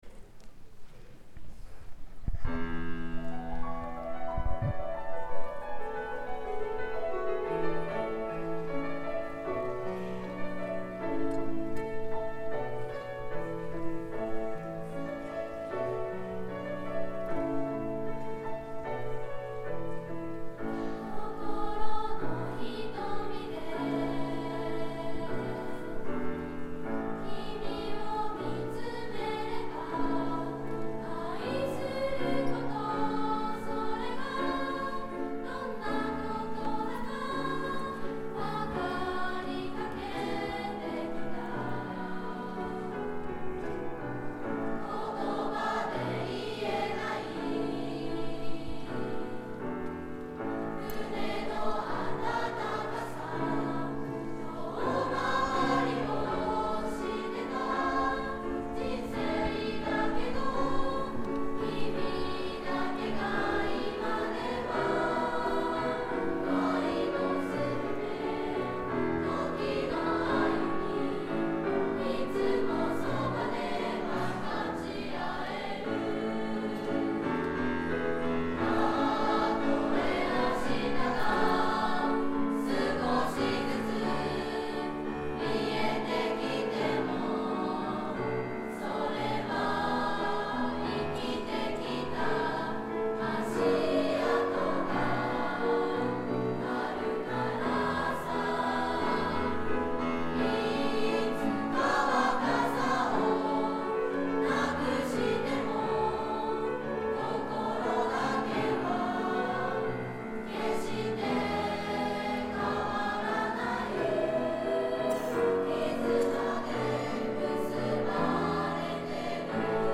２Ｃ 心の瞳.mp3 ←クリックすると合唱が聴けます